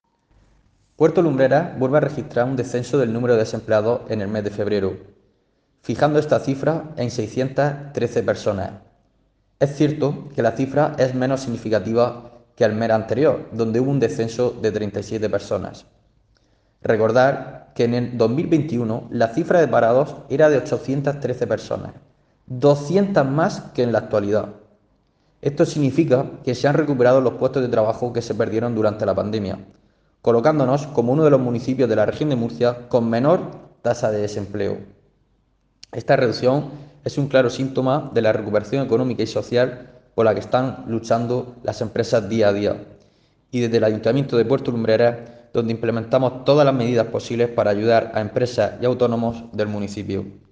Declaraciones-concejal-de-Empleo-Jose-Manuel-Sanchez-sobre-los-datos-del-paro.ogg